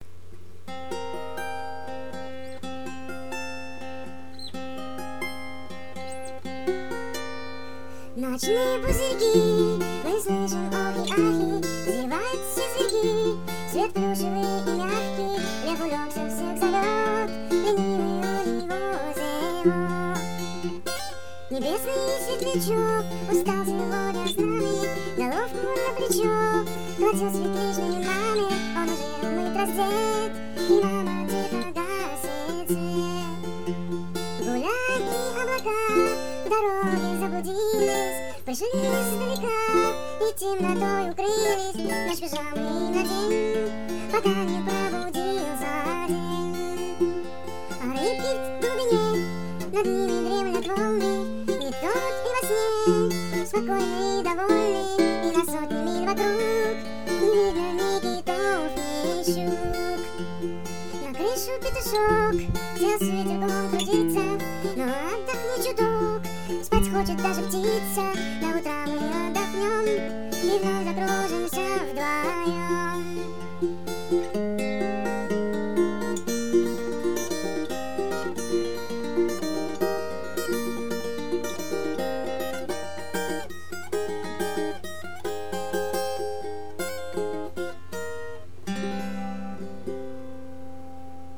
Колыбельная написана по заказу на смену